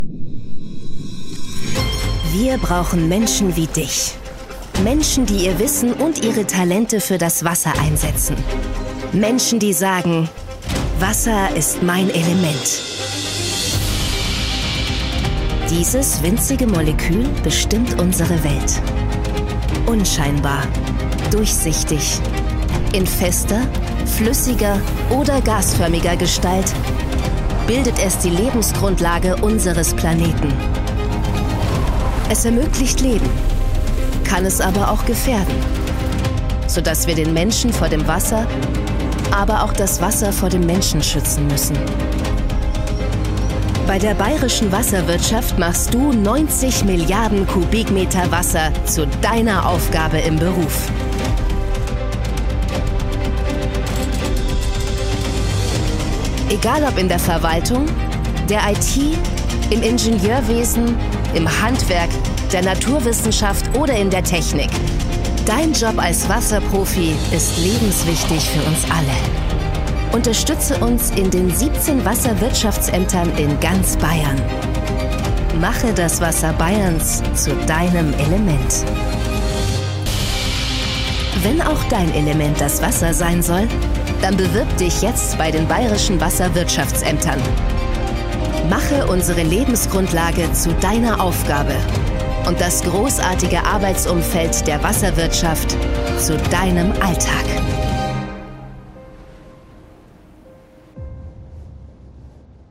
markant, sehr variabel, hell, fein, zart
Jung (18-30)
Presentation